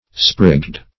Sprigged \Sprigged\ (spr[i^]gd or spr[i^]g"g[e^]d), a.